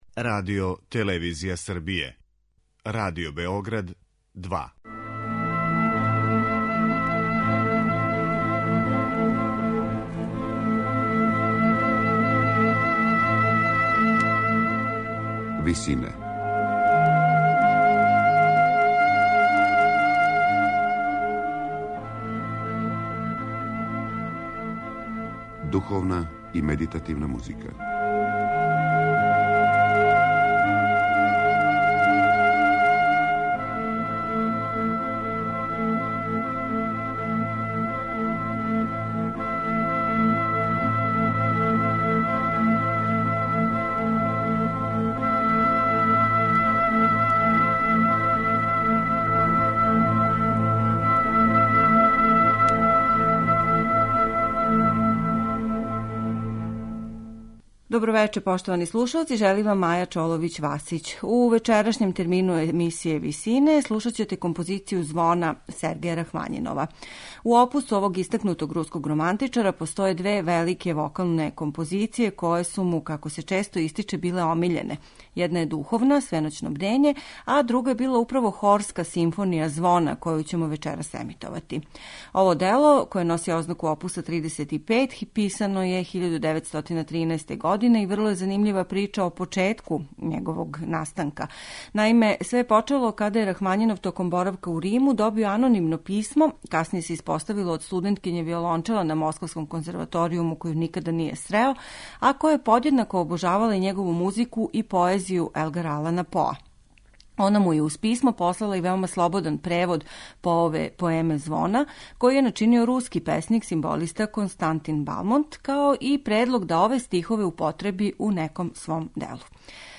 Слушаћете хорску симфонију 'Звона' Сергеја Рахмањинова
Шкотским националним хором и оркестром диригује Неме Јерви.